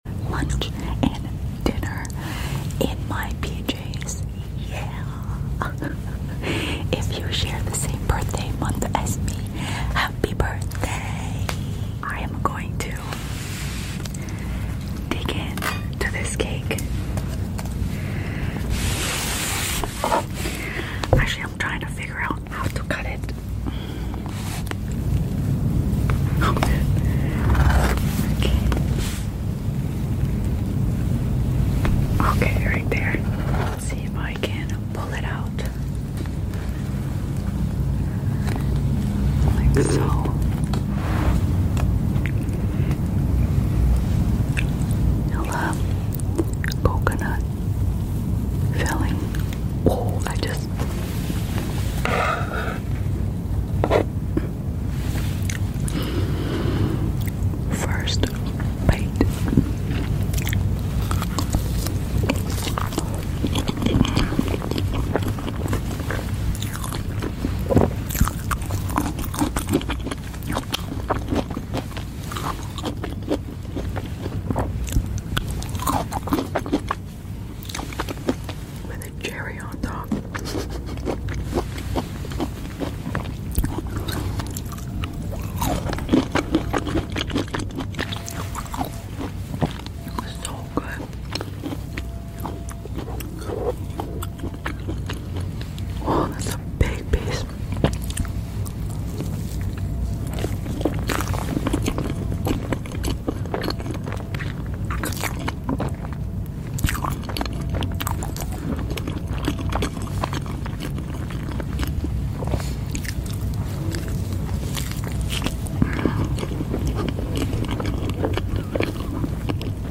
ASMR CAKE FOR BREAKFAST PART sound effects free download
ASMR CAKE FOR BREAKFAST PART 1 (EATING SOUNDS) LIGHT WHISPERS